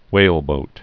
(wālbōt, hwāl-)